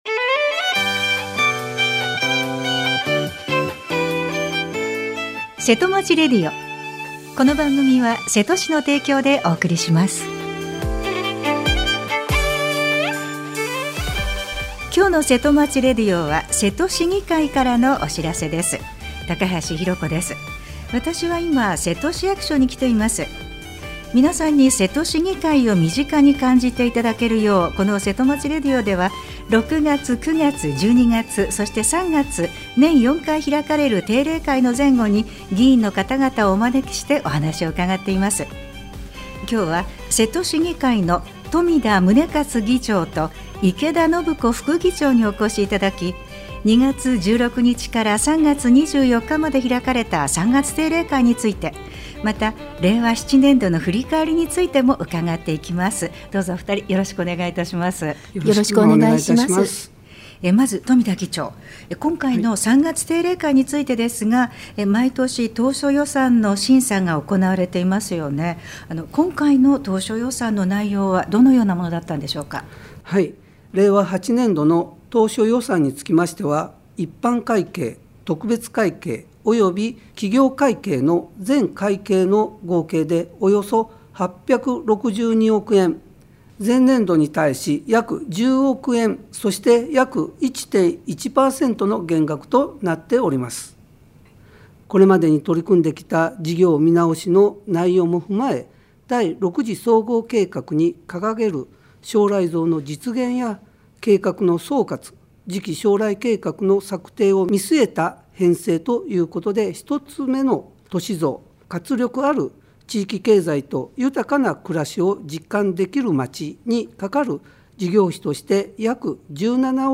瀬戸市議会の冨田宗一議長 と 池田信子副議長に、 「 ３月定例会と令和７年度の振り返り 」 についてお話しを伺いました。